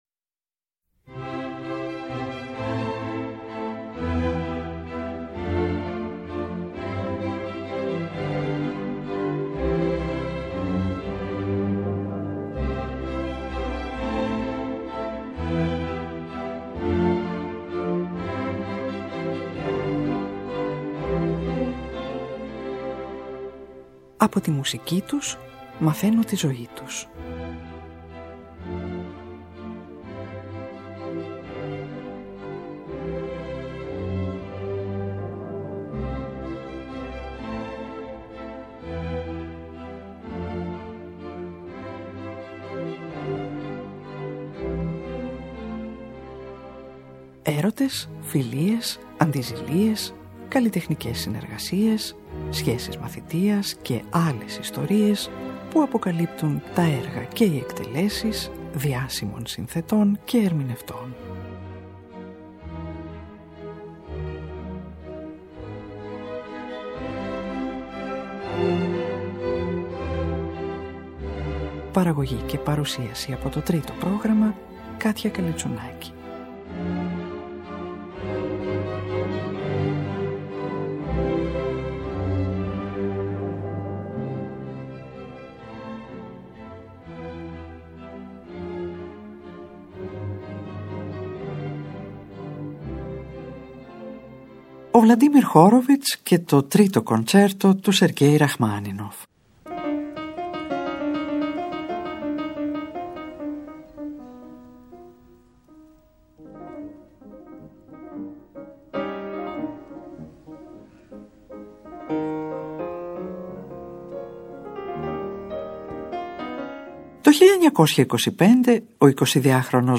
από ηχογράφηση του 1951 στη Ν.Υόρκη
Κοντσερτο για πιανο και ορχηστρα αρ. 3 σε ρε ελασσονα